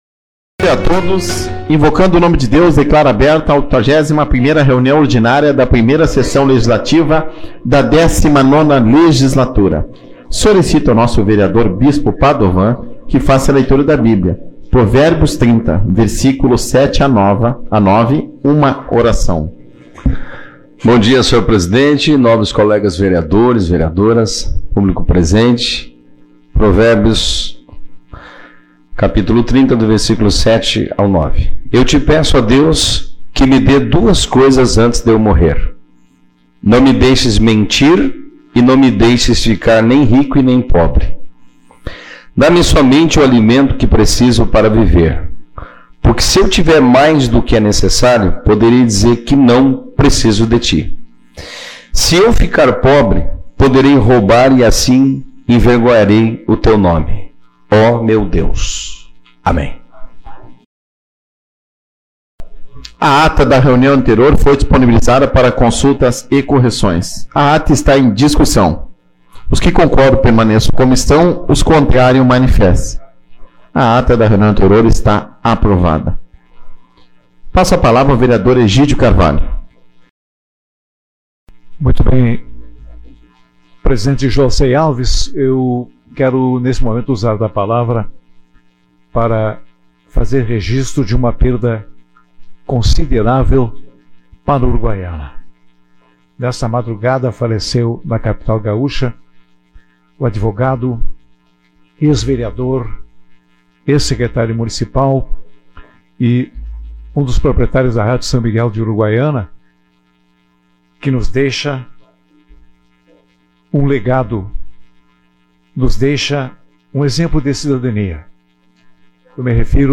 09/12 - Reunião Ordinária